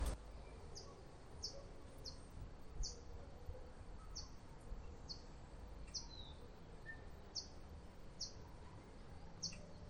Chingolo (Zonotrichia capensis)
Nombre en inglés: Rufous-collared Sparrow
Localidad o área protegida: Gran Buenos Aires Norte
Condición: Silvestre
Certeza: Observada, Vocalización Grabada